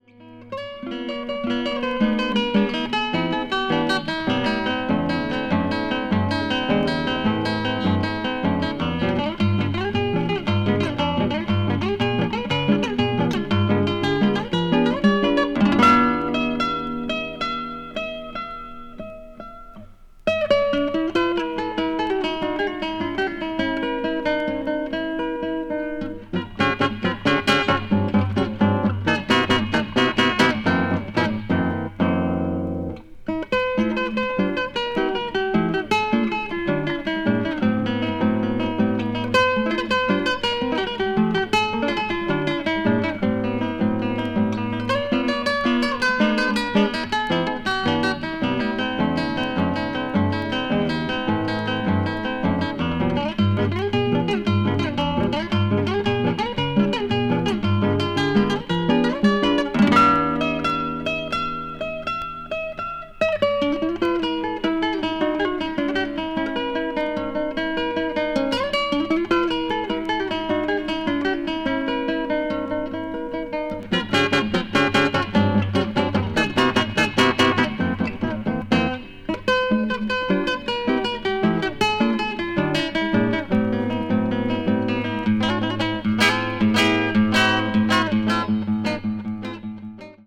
acoustic   brazil   mpb   world music